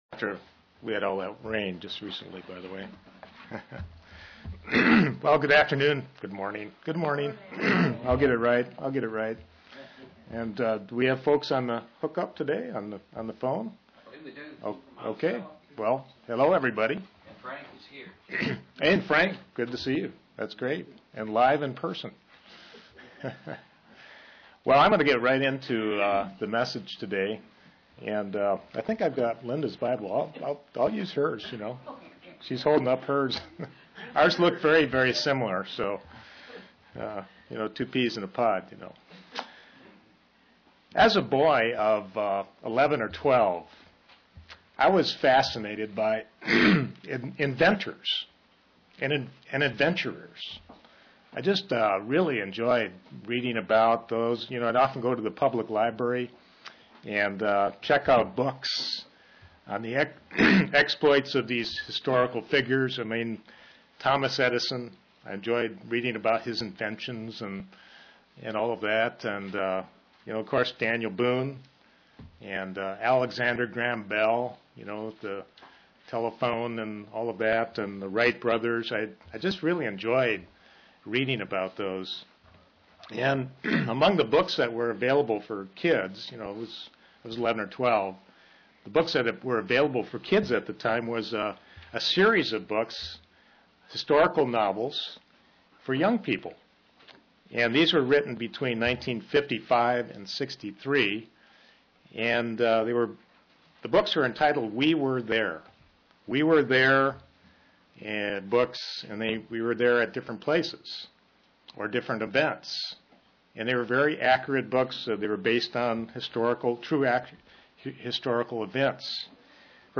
Given in London, KY
Print Life of a Christian is the greatest adventure possible UCG Sermon Studying the bible?